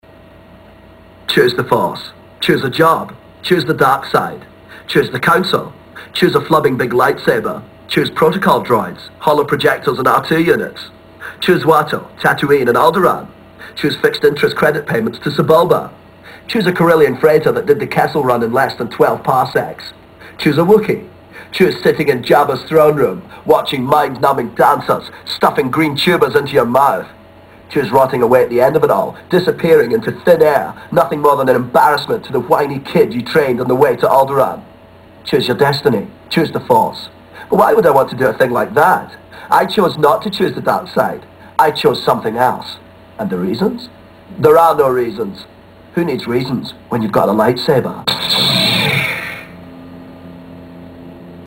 This weekend I managed to finish Obi-Wan, an older title to be sure, but after having watched a friend play through it some time ago, I'd been meaning to play through it myself. After finishing it I thought I'd make a recording of the monolouge that is a spoof of another Ewan Mcgregor character (Renton from Trainspotting) accept instead of being about heroin it takes on a Star Wars twist.